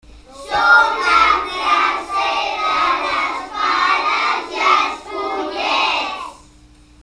Els nens i nenes de la classe de les Fades i Follets ja han començat a tocar la flauta i sona així de bé!
Presentació